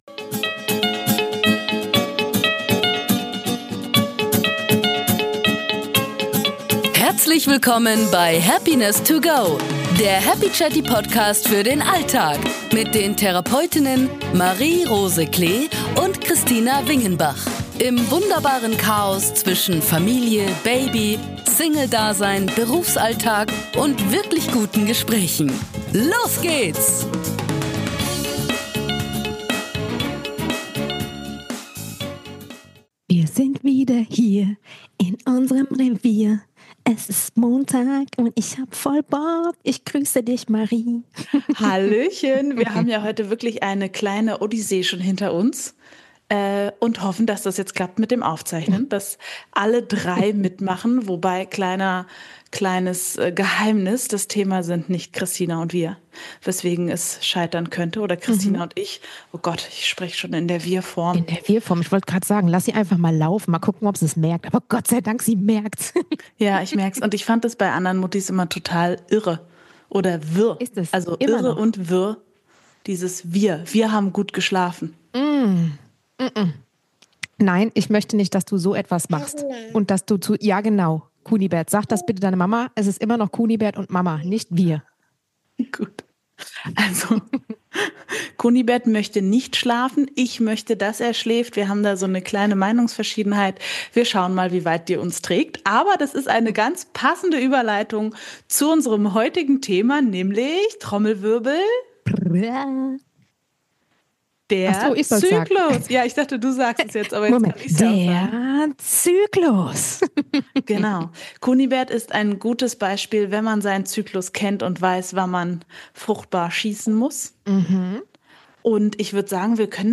In "Happiness to go" nehmen euch zwei Therapeutinnen mit auf eine Reise durch die Höhen und Tiefen des Alltags und der Therapie.